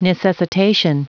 Prononciation du mot necessitation en anglais (fichier audio)